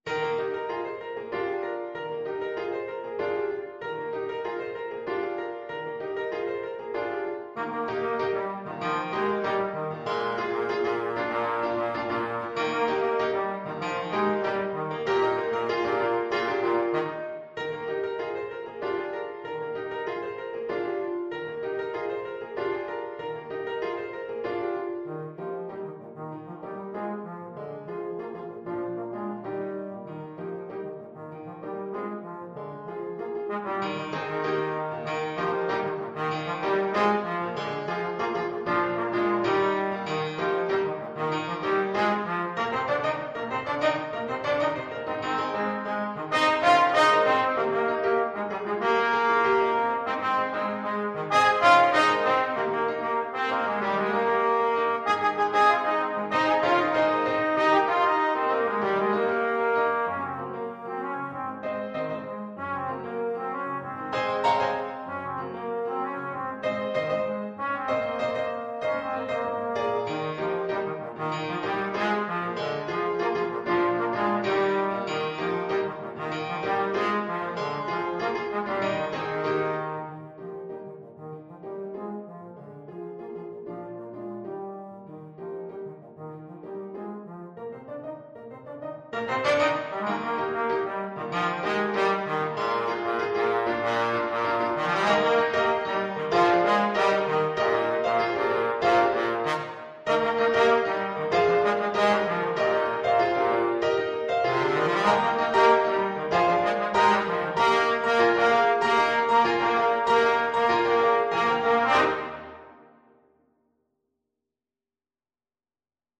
4/4 (View more 4/4 Music)
Bb3-Ab5